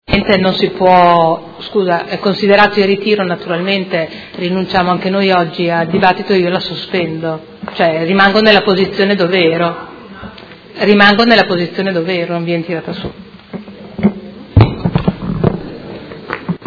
Seduta del 26/10/2017. Chiede sospensione Ordine del Giorno presentato dai Consiglieri Liotti, Bortolamasi, Baracchi, Pacchioni, Arletti, Venturelli, Di Padova e De Lillo (PD) avente per oggetto: Ferma condanna degli atti vandalici al Parco della Resistenza e ripristiniamo il Parco insieme